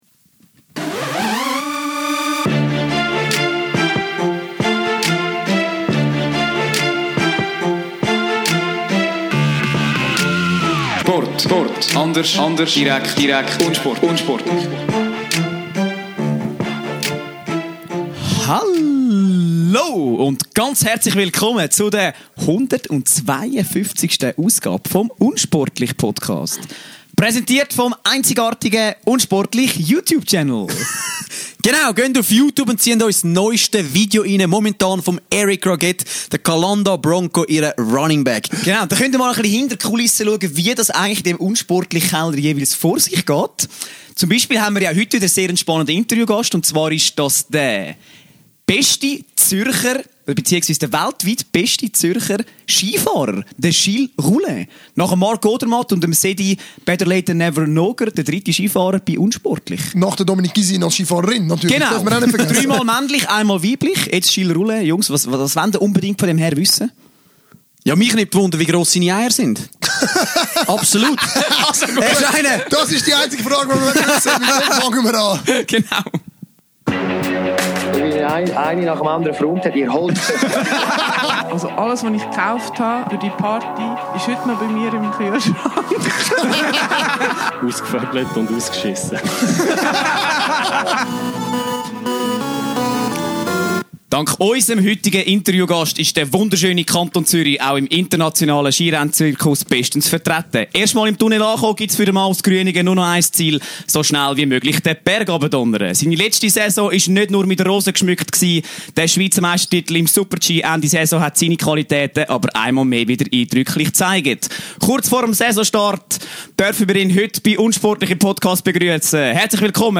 Noch nie haben wir so viel Aufwand für ein Interview betrieben.
Zum Schluss kann sich der Sympathieträger aus dem Zürcher Oberland das Lachen aber nicht mehr verkneifen.